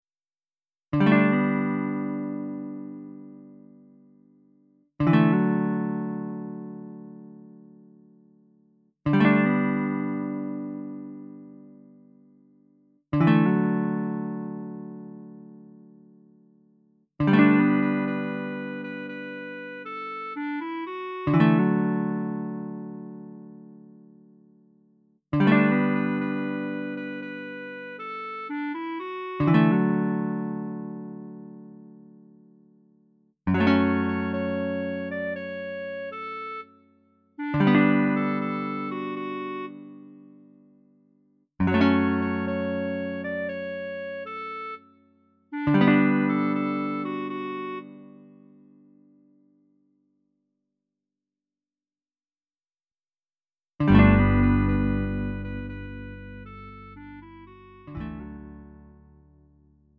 음정 원키 4:31
장르 구분 Lite MR